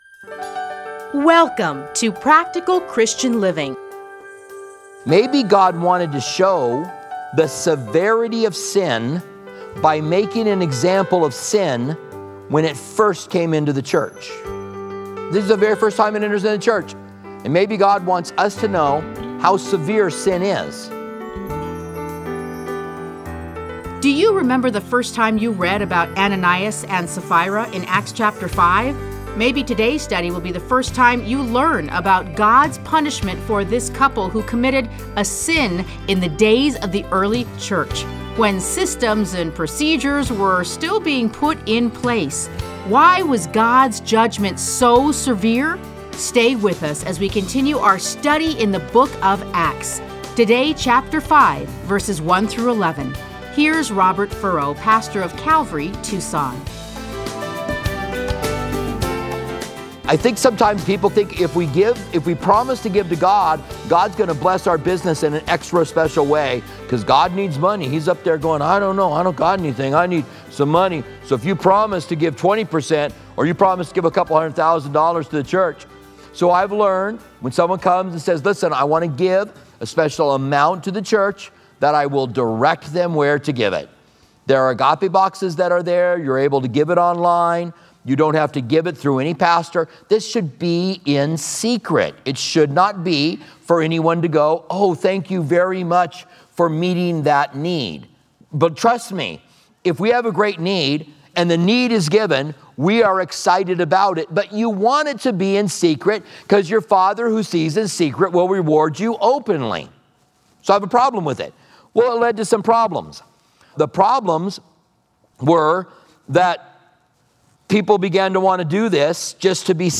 Listen to a teaching from Acts 5:1-11.